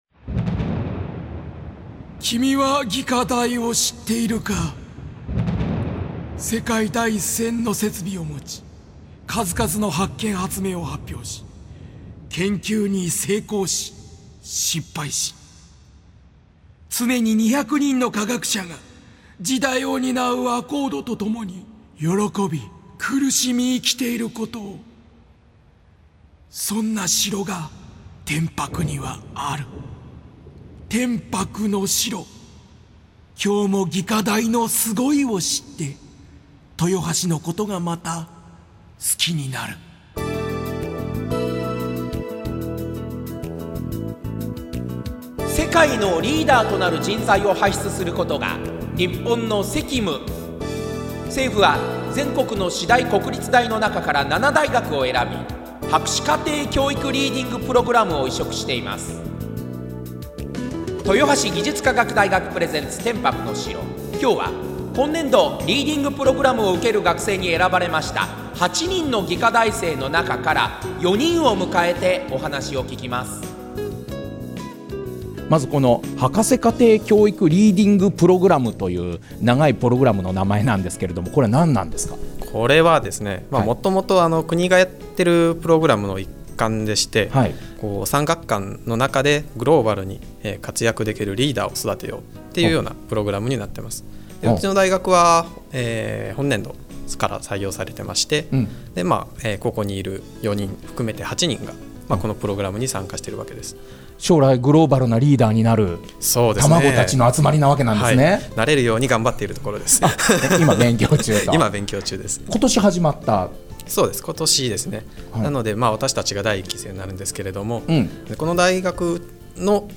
FM豊橋の「天伯之城」に履修生4名が出演し、リーディングプログラムの概要、 今夏にマレーシアで行われたグローバルサマースクールについてのエピソード等を話しました。